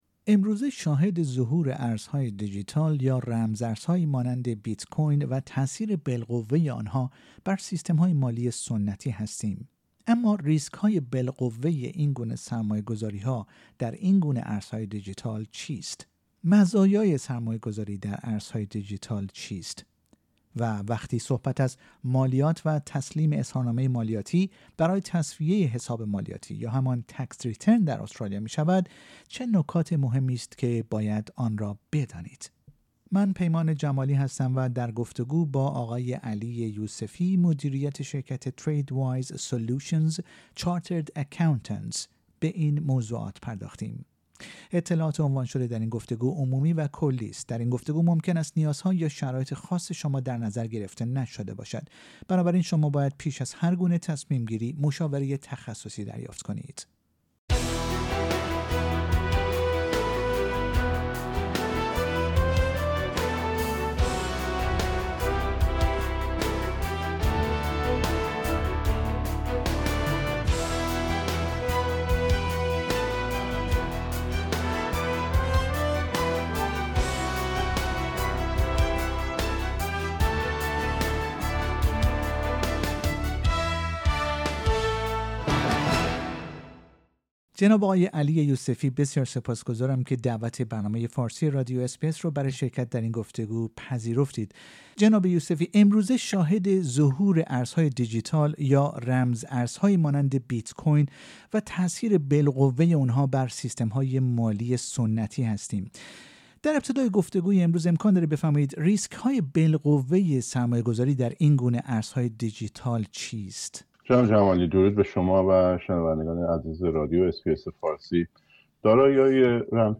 اطلاعات عنوان شده در این گفتگو، عمومی و کلی است.